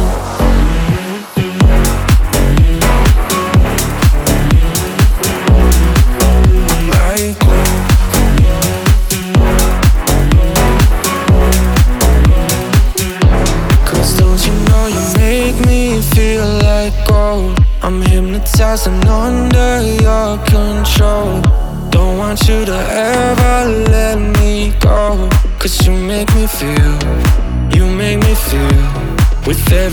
• Dance
dance-pop hybrid track